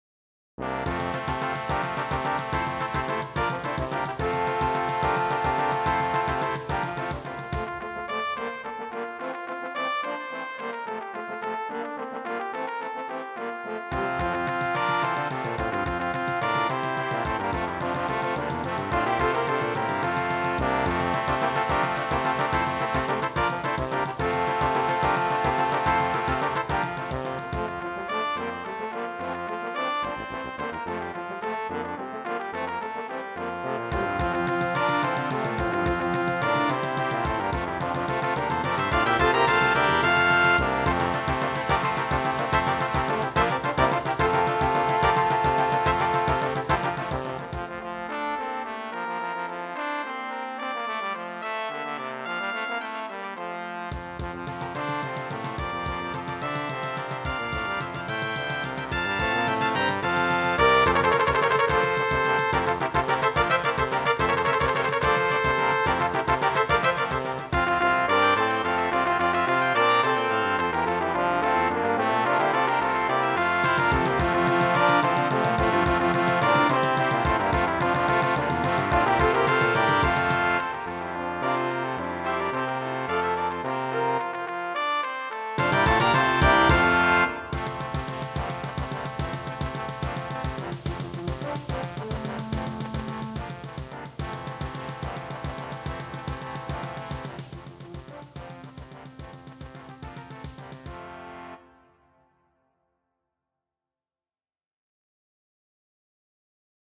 traditional folk carol